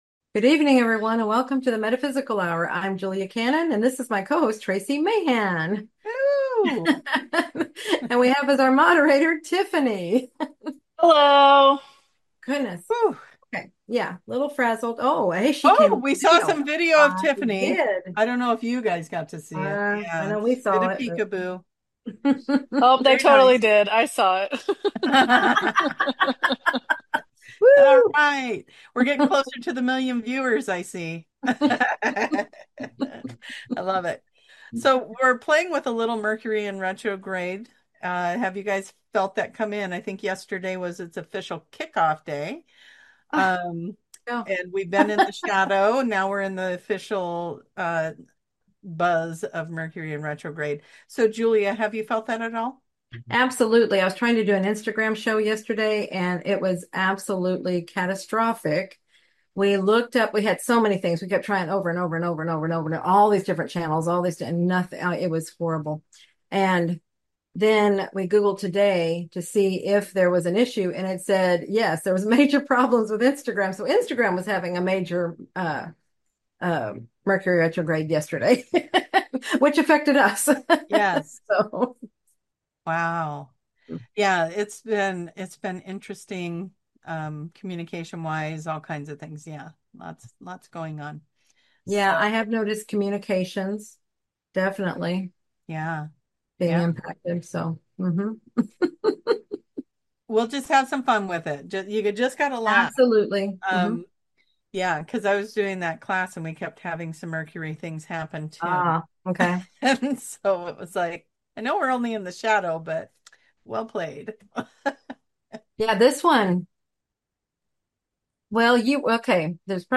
Answering viewer questions